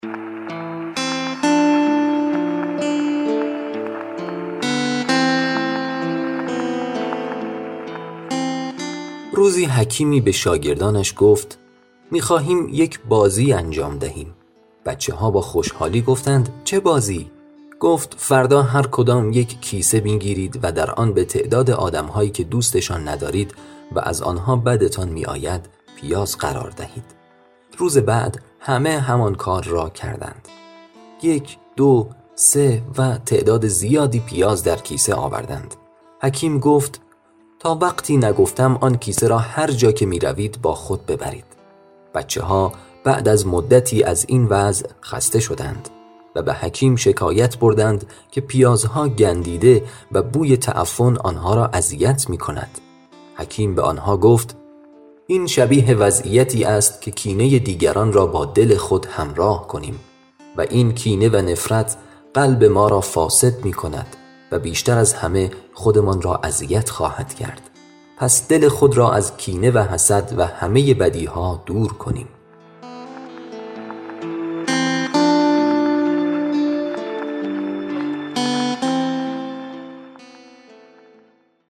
داستان کوتاه